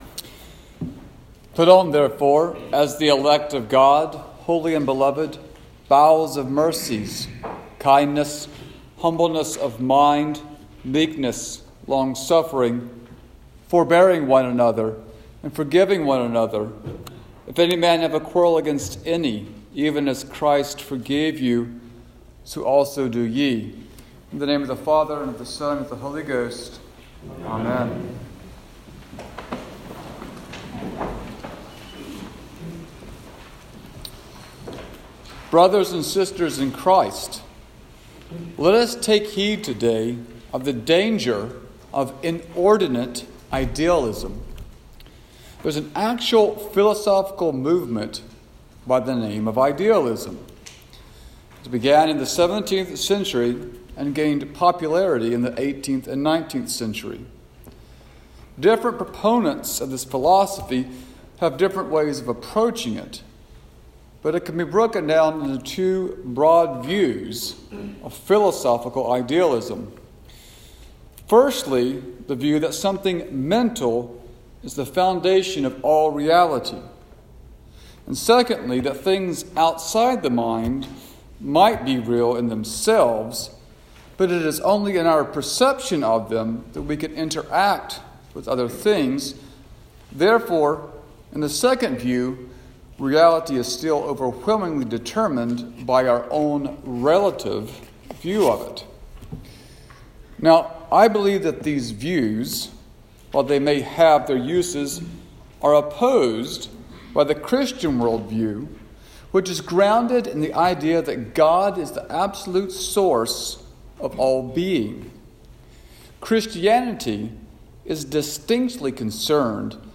Sermon for Epiphany 5